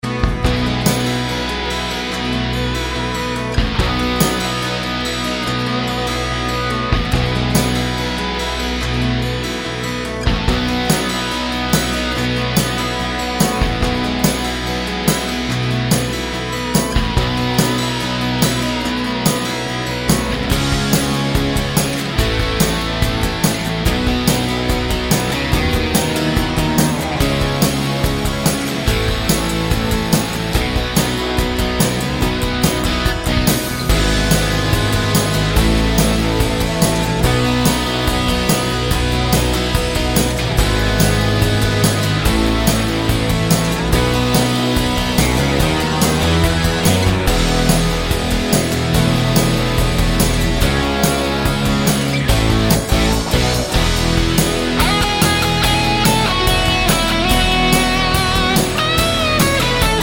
Minus Drums Rock 4:00 Buy £1.50